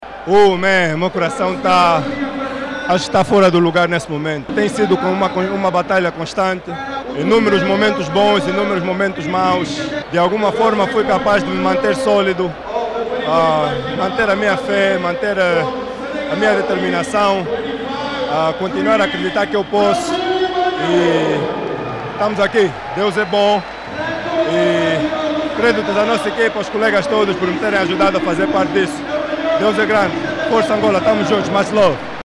O postes da selecção nacional, Bruno Fernando, emocionado, destacou a importância do momento e referenciou a dedicação e a entrega de todos na garantia da consagração.